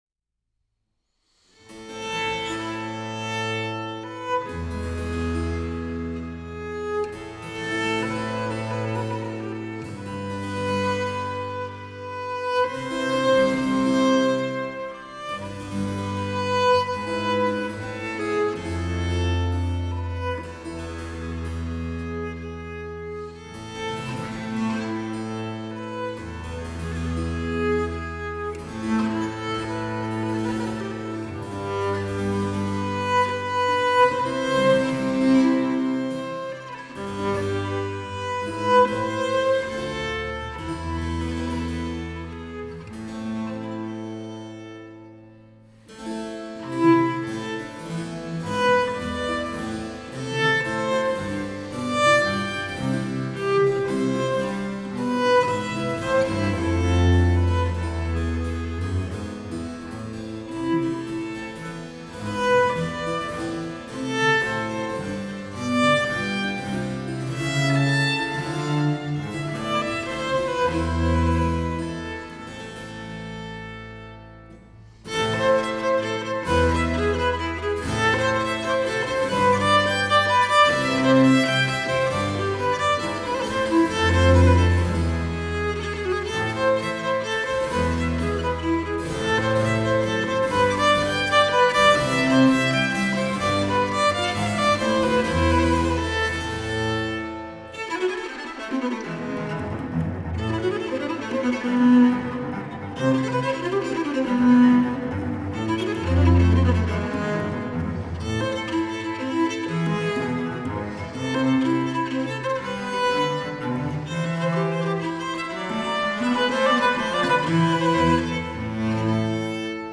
Sonata en re menor Op.5 N°12 'La Folía'